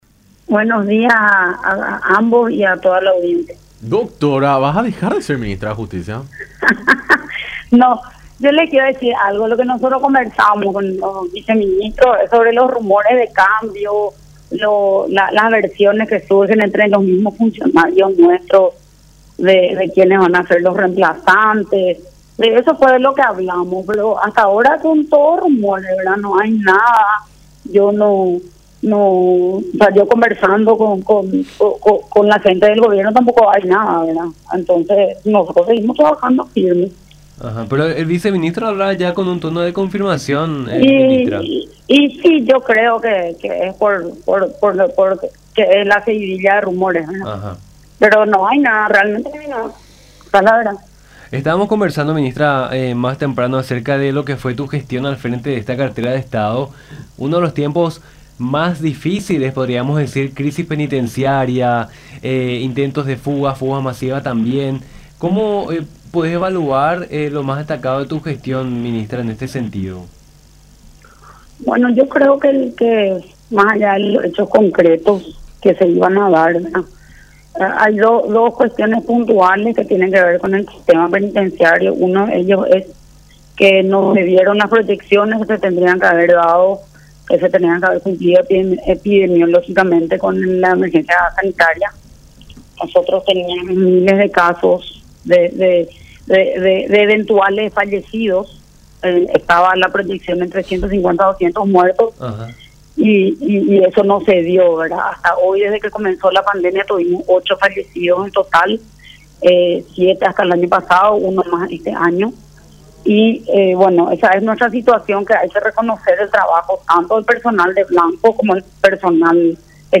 “Hasta ahora, son solamente rumores y versiones que van a vienen. De momento al menos no hay nada. Acá seguimos trabajando firmes”, afirmó Pérez en conversación con Nuestra Mañana por La Unión, contradiciendo a la versión surgida esta mañana sobre su supuesta salida del cargo.